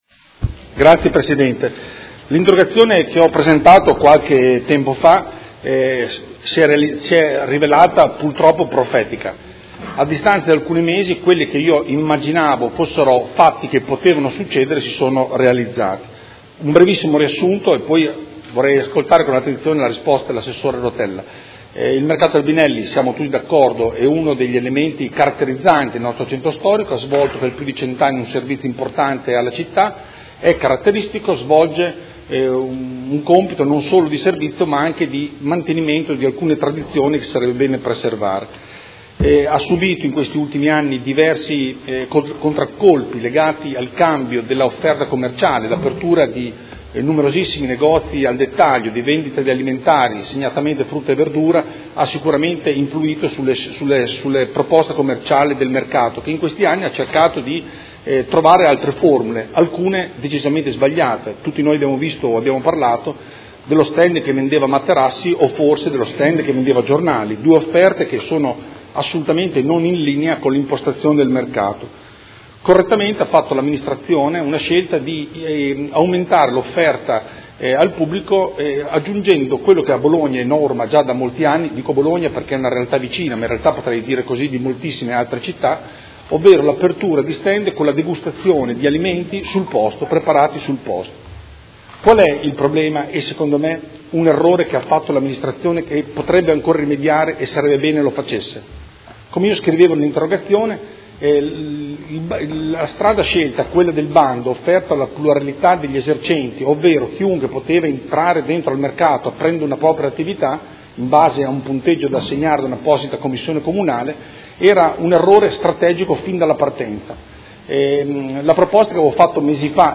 Seduta del 2/2/2017. Interrogazione del Consigliere Galli (F.I.) avente per oggetto: Nuove aperture al Mercato Albinelli; ma davvero l’Amministrazione crede di aiutare?